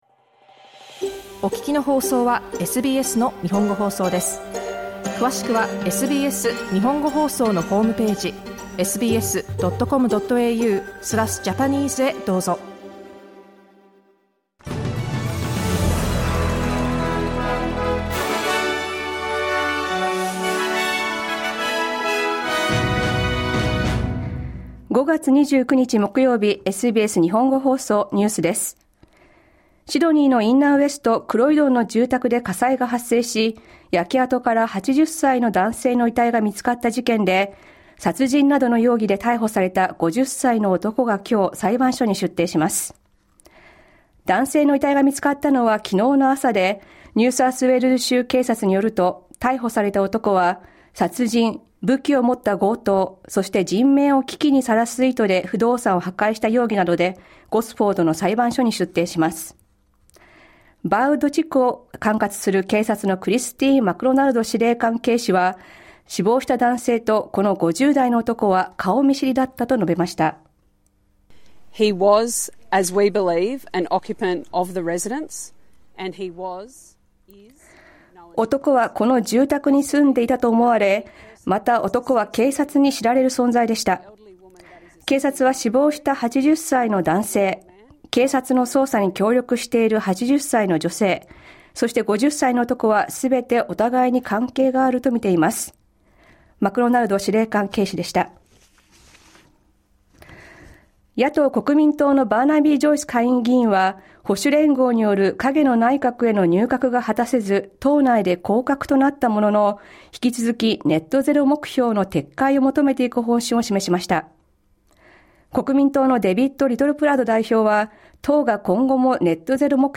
SBS日本語放送ニュース5月29日木曜日